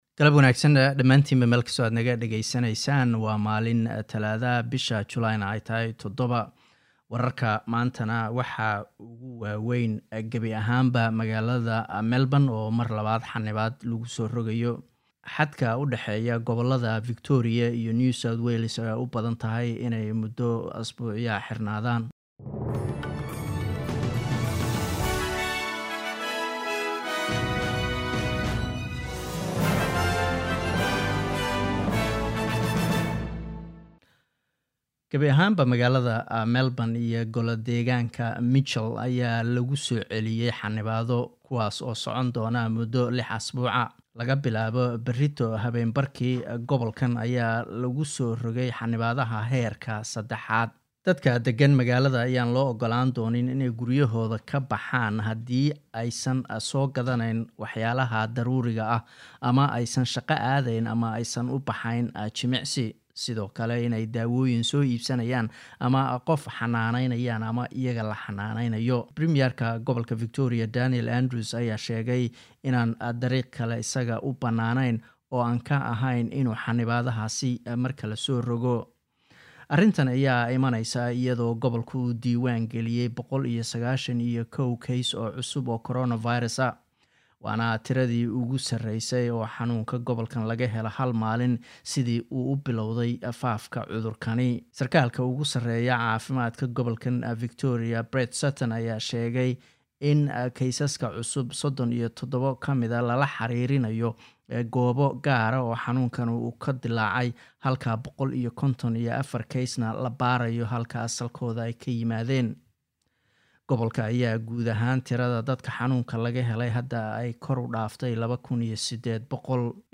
Wararka SBS Somali Talaado 7 July